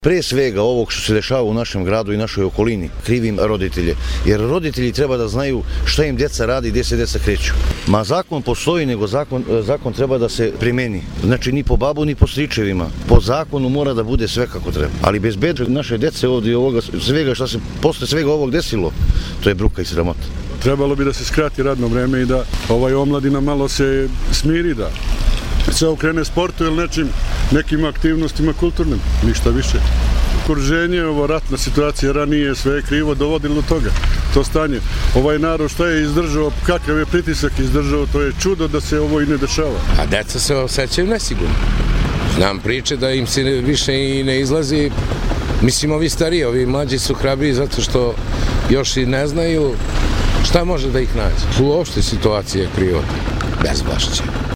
Roditelji o bezbednosti